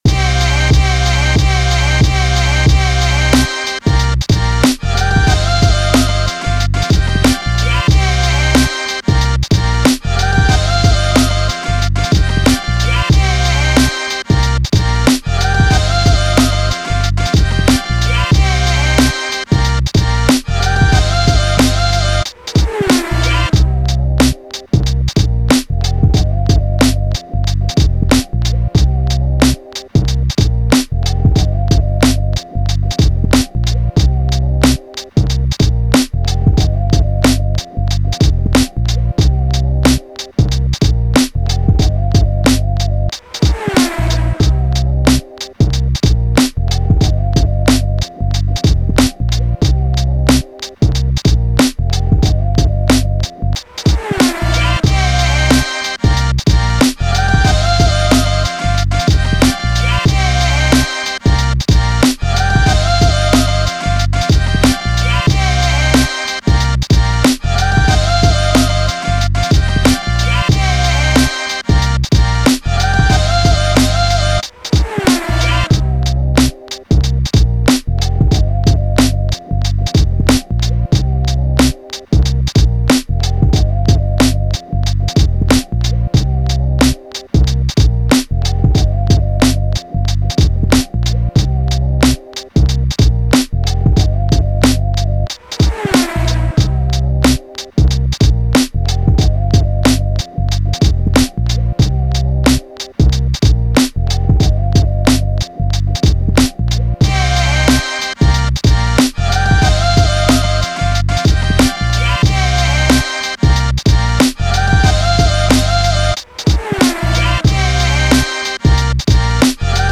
Hip Hop
C minor